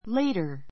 léitə r